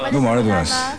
All sounds in WAV format are spoken by Yusaku himself (Also Andy Garcia and Michael Douglas).
From the press conference when he was awarded best leading actor in The Family Game.